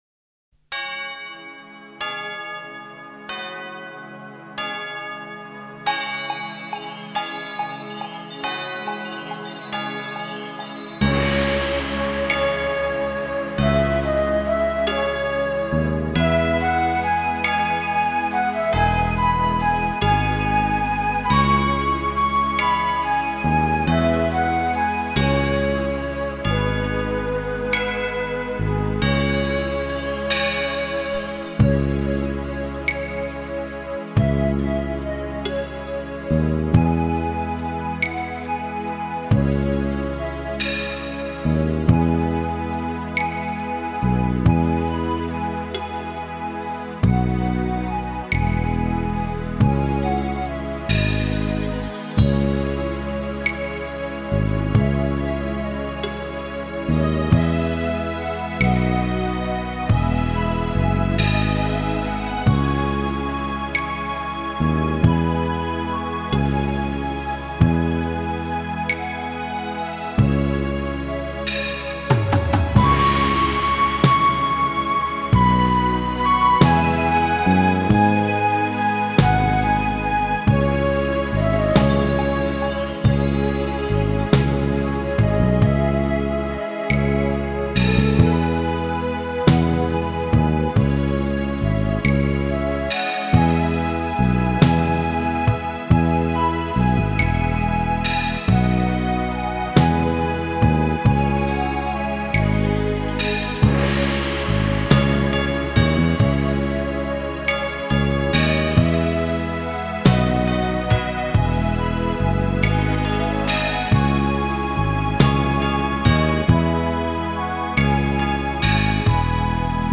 G调
韩国佛曲
吉他
二胡
笛子
扬琴